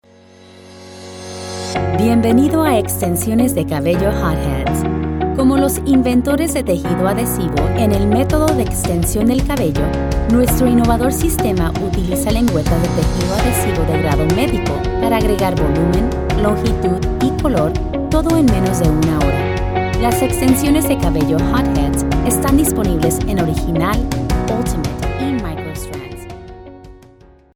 Female Voice Over, Dan Wachs Talent Agency.
Bilingual Voice Actor.  English, Neutral Spanish, Columbian and Mexican Dialects.
eLearning - Spanish